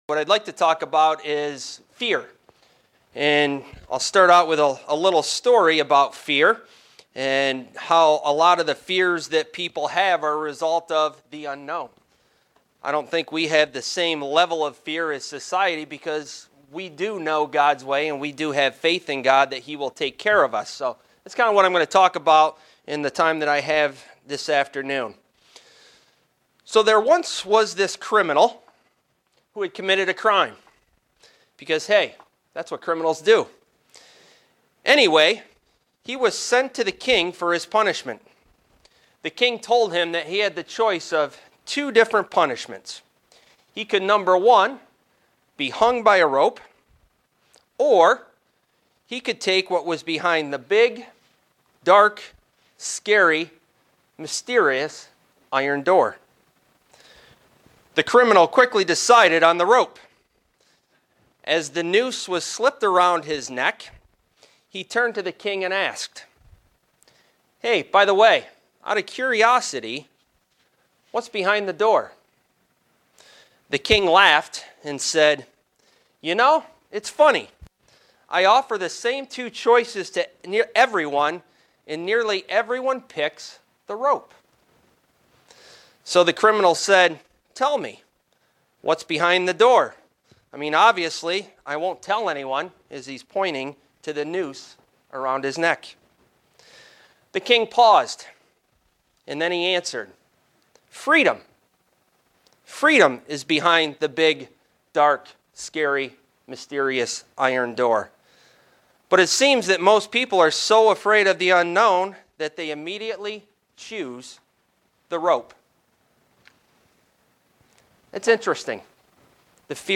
Given in Buffalo, NY